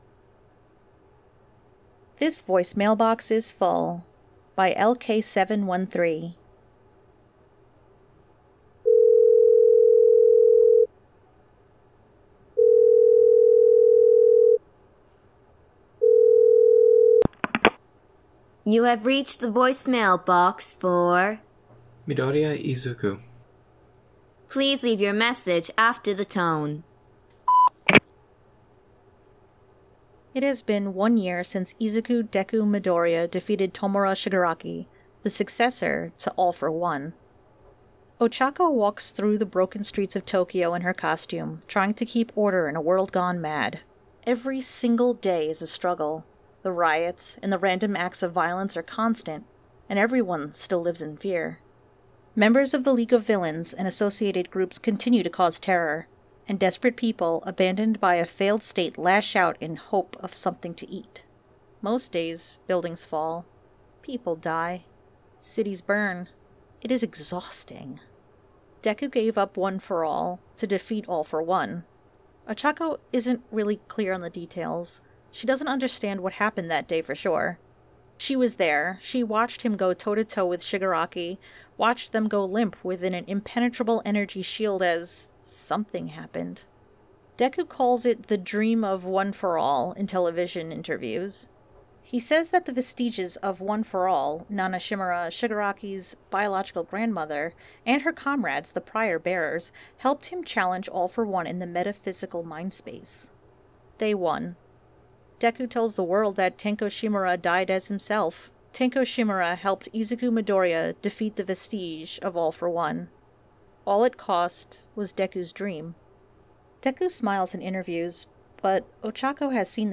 This podfic was voiced and produced by 4 of our contributors.